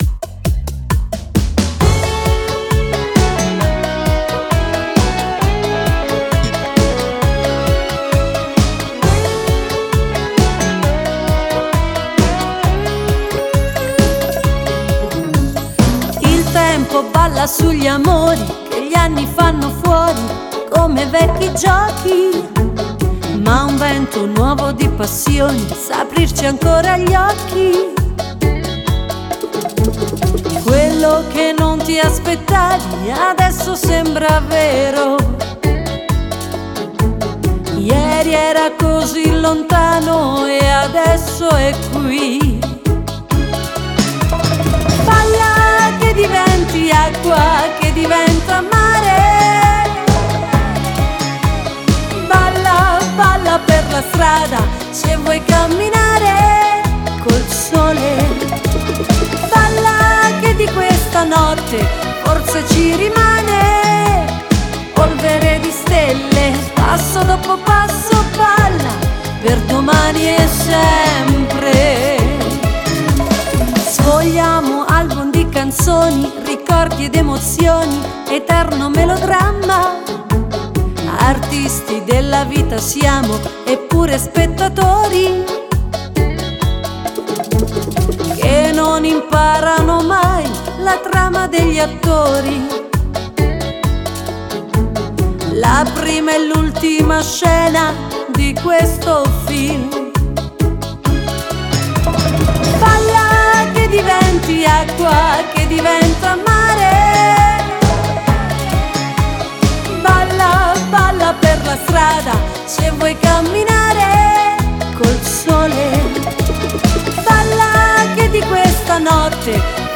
Bachata
Bellissima canzone pop a ritmo di bachata